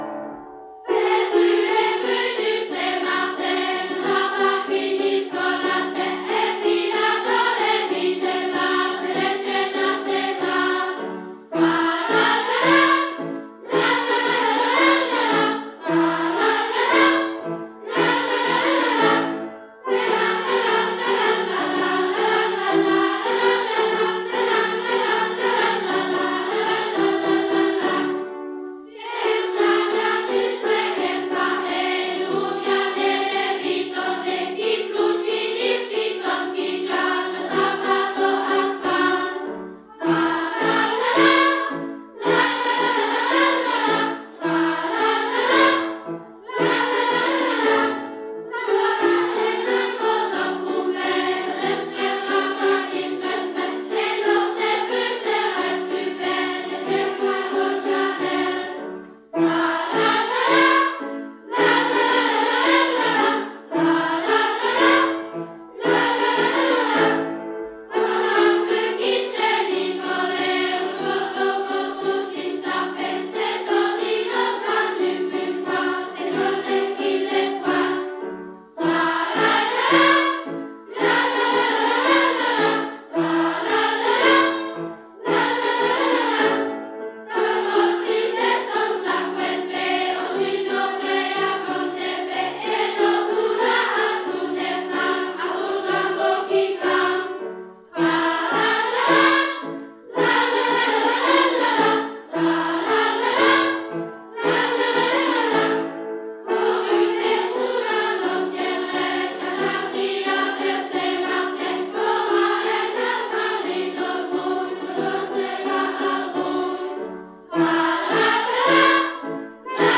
Genre : chant
Type : chanson calendaire
Aire culturelle d'origine : Haute Ardenne
Interprète(s) : Anonyme (enfant)
Lieu d'enregistrement : Malmedy
Support : bande magnétique
Chantée par une chorale d'enfants avec accompagnement au piano.